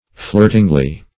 flirtingly - definition of flirtingly - synonyms, pronunciation, spelling from Free Dictionary Search Result for " flirtingly" : The Collaborative International Dictionary of English v.0.48: Flirtingly \Flirt"ing*ly\, adv. In a flirting manner.